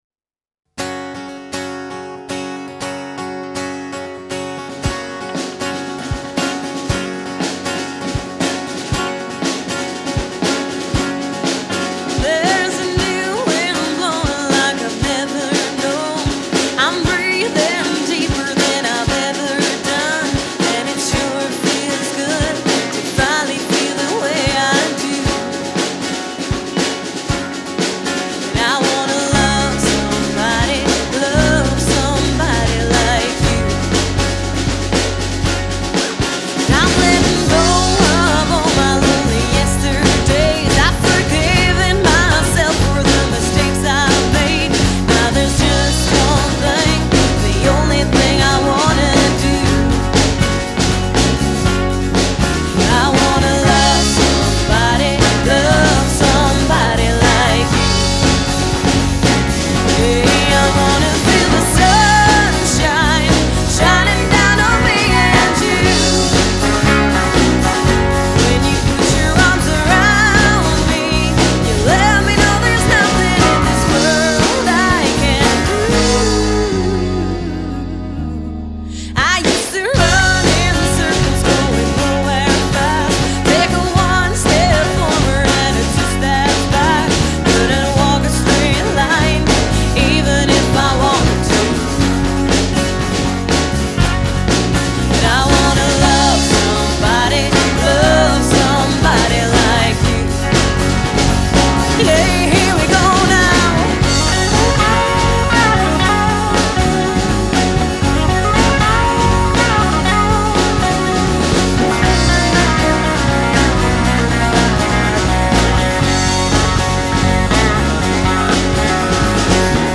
Solo guitar and singing
Lead singer
Guitar and singing
Bass guitar
Drums